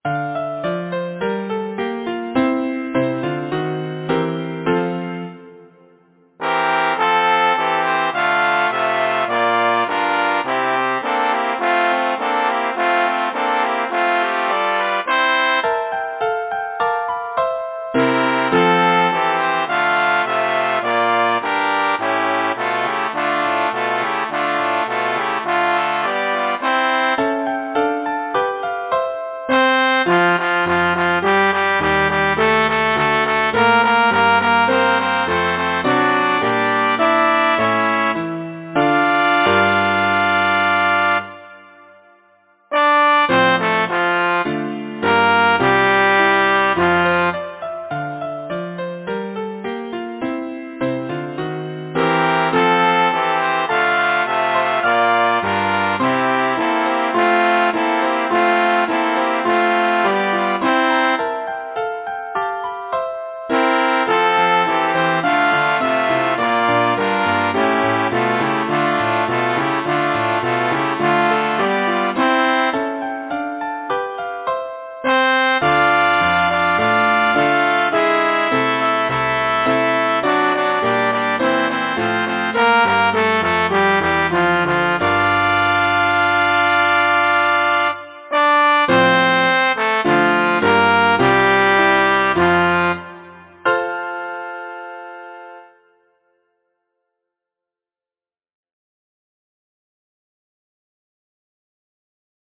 Title: The Traction Engine Composer: Stanley Marchant Lyricist: Hugh Chesterman Number of voices: 4vv Voicing: SATB Genre: Secular, Partsong
Language: English Instruments: Piano
First published: 1927 Novello and Co. Description: From “Nonsense Songs” – Arranged by composer as a four Part Song